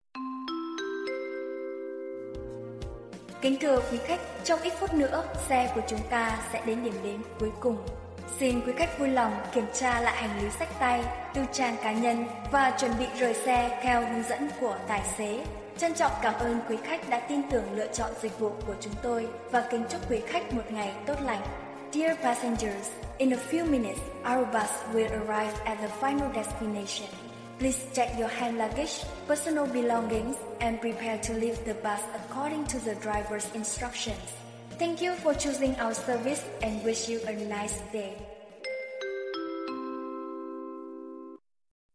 Nhạc chuông tiếng Sáo thổi Lời chào Xuống xe, Kính thưa quý khách, chỉ còn ít phút nữa, xe của chúng ta sẽ tới điểm đến…
Thể loại: Tiếng chuông, còi
File âm thanh lời chào tiễn khách, thông báo đến điểm đỗ, cảm ơn khách hàng đã sử dụng dịch vụ với giọng nữ trung, cao chuyên nghiệp bằng tiếng Việt và tiếng Anh. Âm thanh rõ ràng, êm tai, tạo ấn tượng tốt khi kết thúc chuyến đi.
am-thanh-carplay-chao-xuong-xe-tra-khach-cho-xe-dich-lich-www_tiengdong_com.mp3